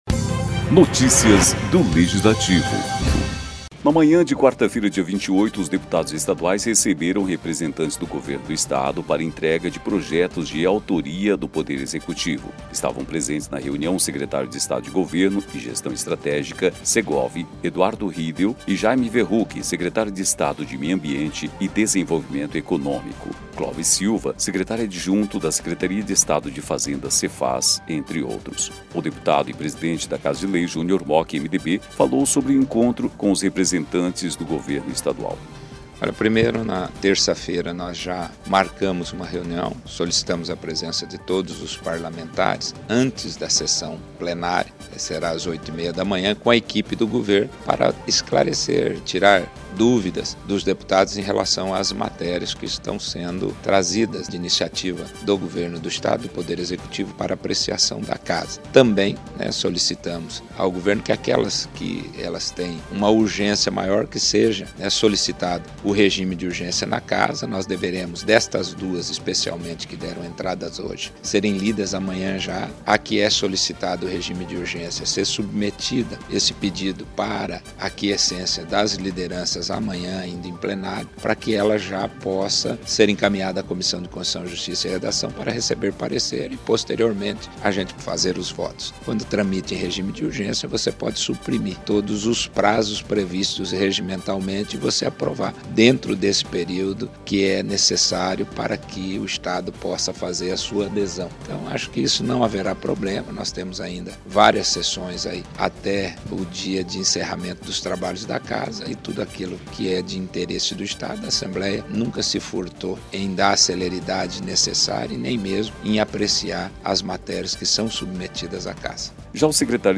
O deputado e presidente Junior Mochi (MDB) falou sobre o encontro com os representantes do governo estadual.
Já o secretário de Estado de Governo e Gestão Estratégica, Eduardo Riedel, comentou sobre os projetos apresentados.